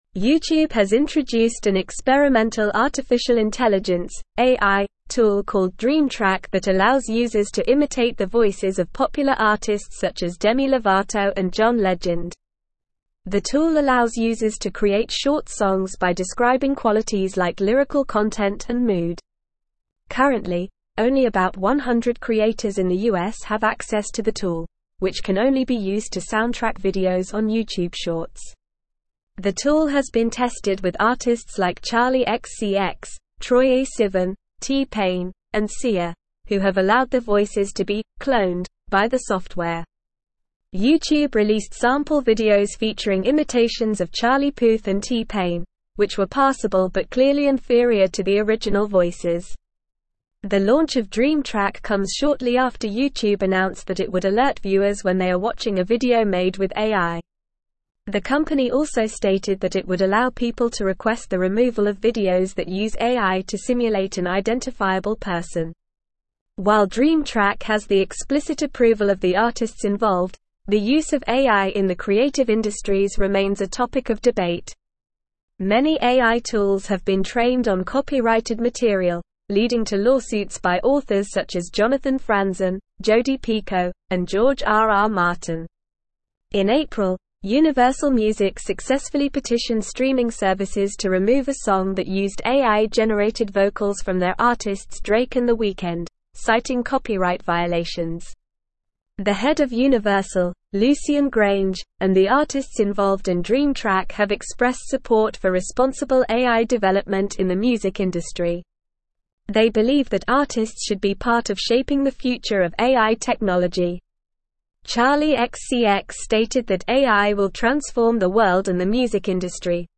Normal
English-Newsroom-Advanced-NORMAL-Reading-YouTube-Unveils-Dream-Track-AI-Tool-Imitates-Artists-Voices.mp3